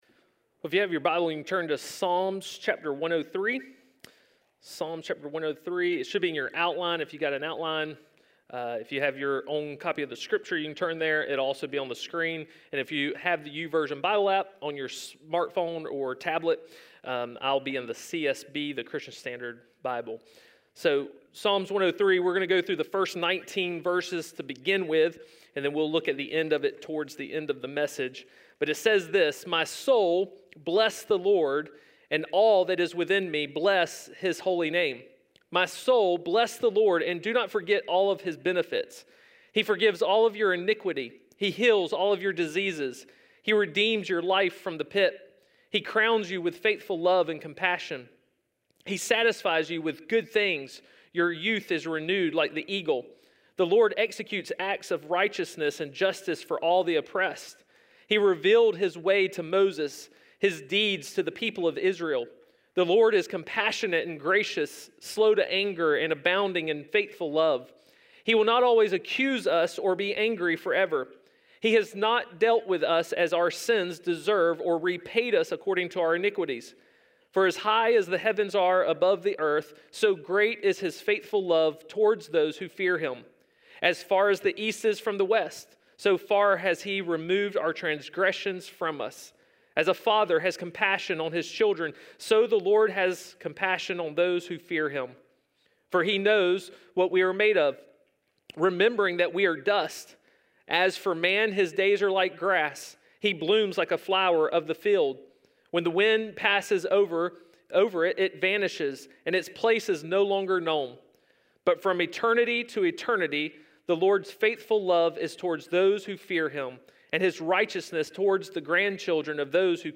A message from the series "Wake Up!."